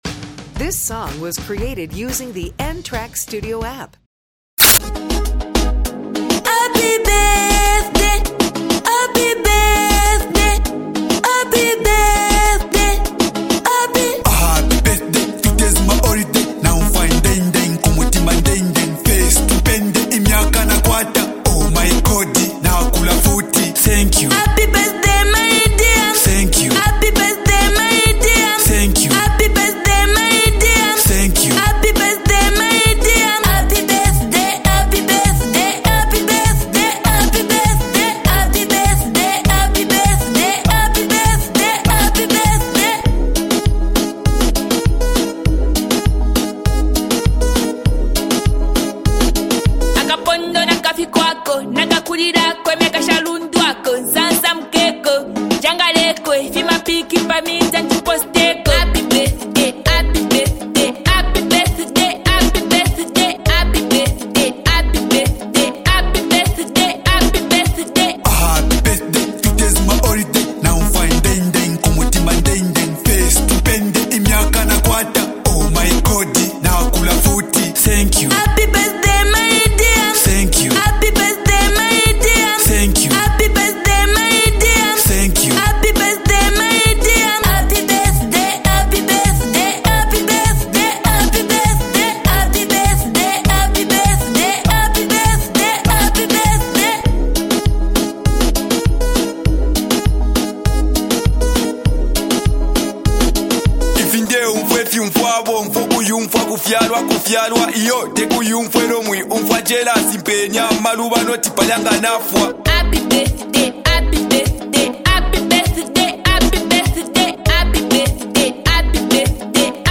joyful, feel-good celebration song